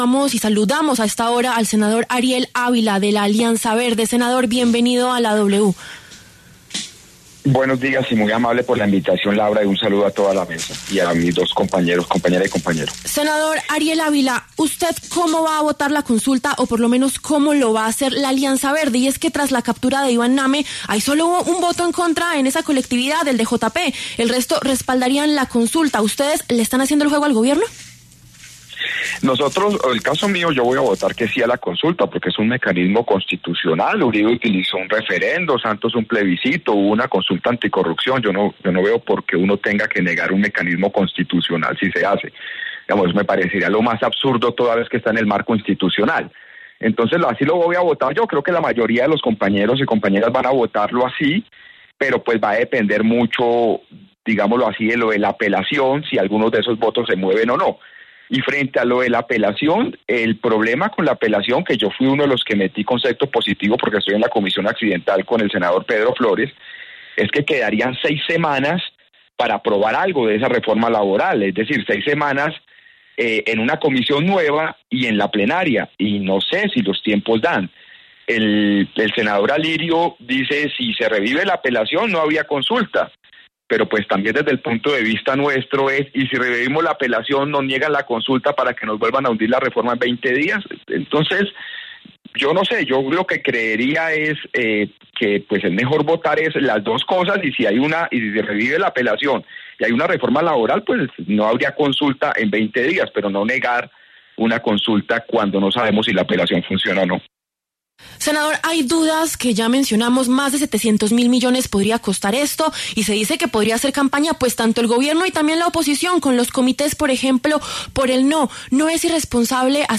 El senador Ariel Ávila de la Alianza Verde pasó por los micrófonos de La W y aseguró que el Gobierno no tiene asegurados los votos necesarios en el Congreso.